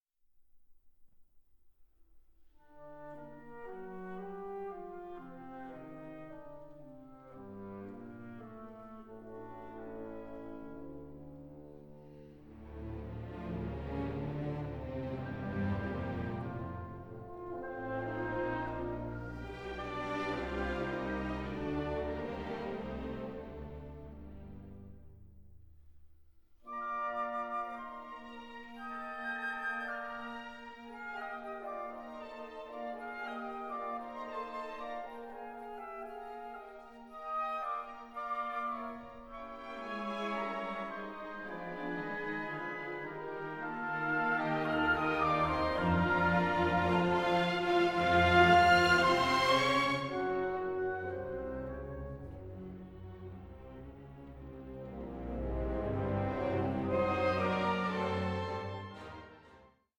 Recorded at the orchestra’s impressive residence